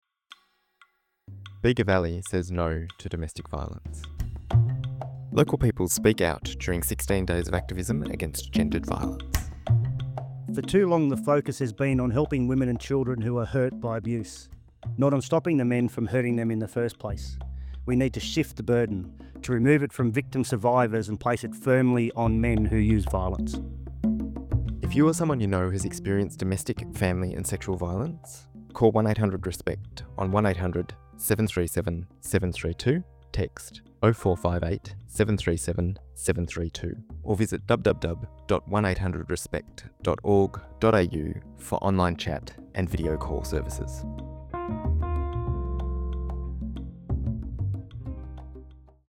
As part of this campaign, we collected brief audio statements from local Bega Valley community members to raise awareness about domestic, family, and sexual violence. These recordings aim to amplify the call to end violence against women and girls and violence in general.